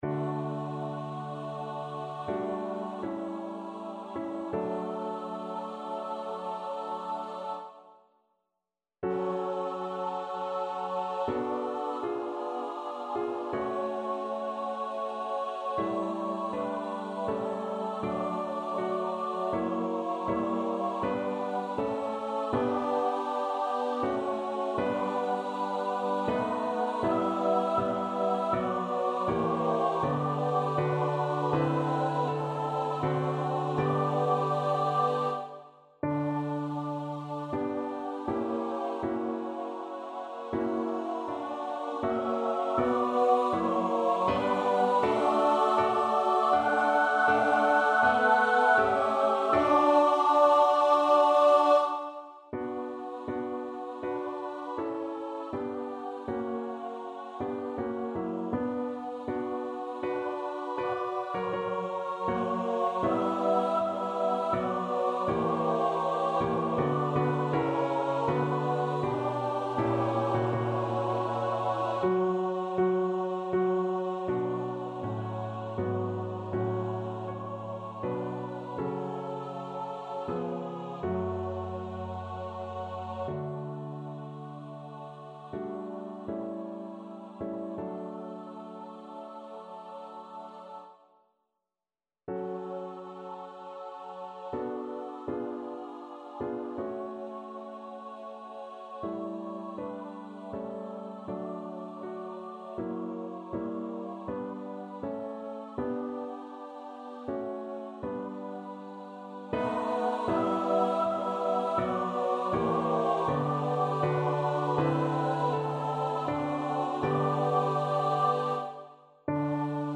Free Sheet music for Choir
3/4 (View more 3/4 Music)
D major (Sounding Pitch) (View more D major Music for Choir )
~ = 80 Andante ma non lento
Choir  (View more Intermediate Choir Music)
Classical (View more Classical Choir Music)